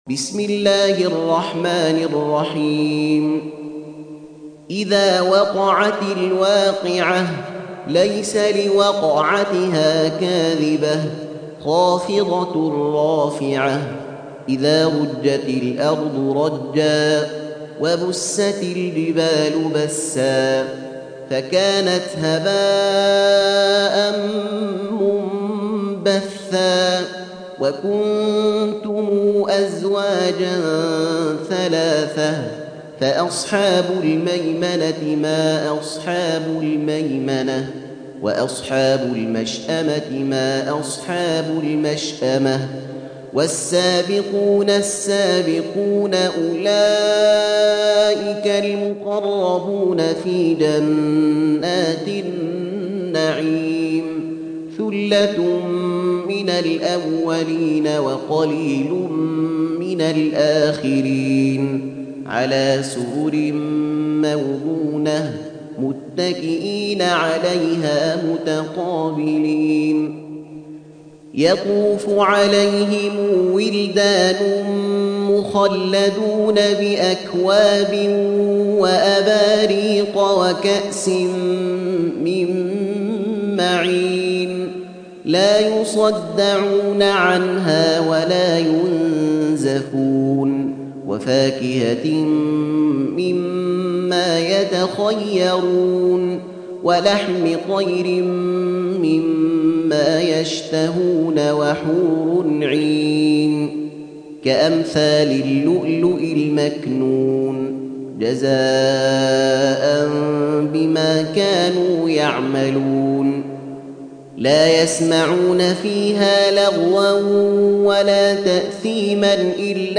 Surah Sequence تتابع السورة Download Surah حمّل السورة Reciting Murattalah Audio for 56. Surah Al-W�qi'ah سورة الواقعة N.B *Surah Includes Al-Basmalah Reciters Sequents تتابع التلاوات Reciters Repeats تكرار التلاوات